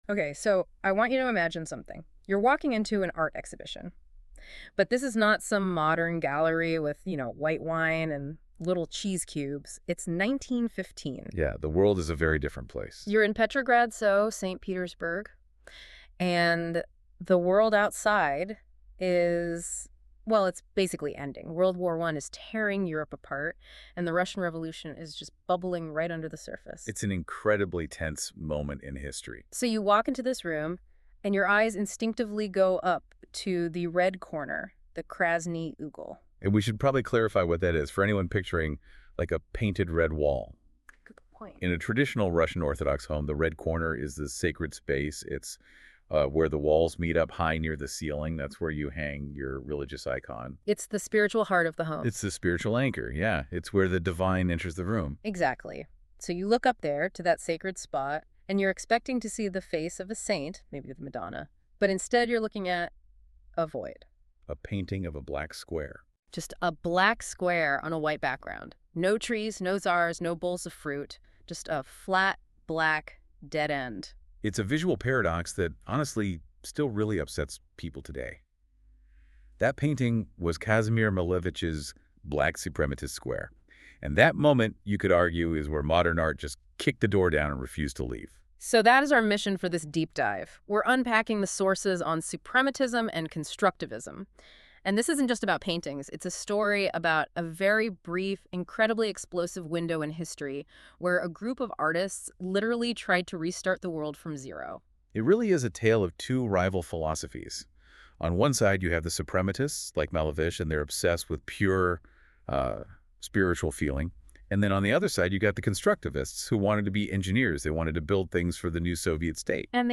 A discussion on Constructivism and Suprematism 1917-1921 (created by NotebookLM from my notes):